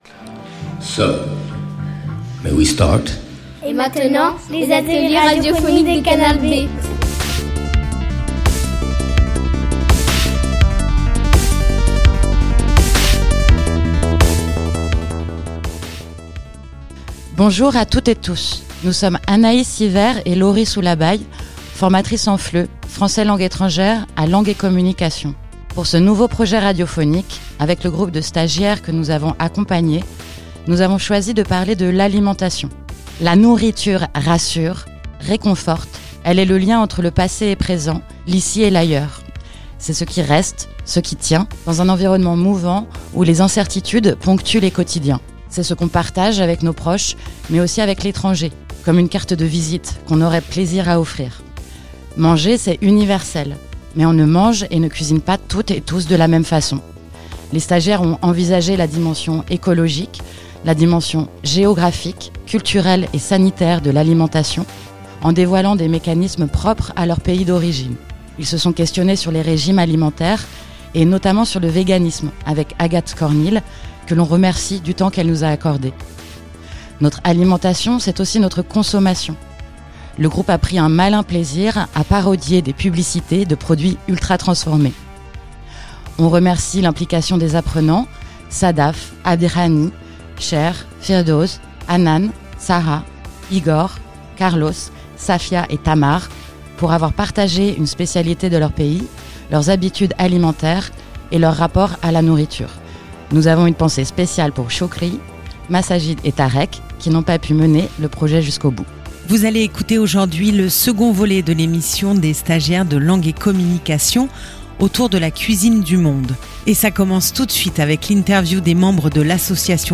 Second volet de l’émission des stagiaires de Langue et Communication autour de l’alimentation et de la cuisine du monde.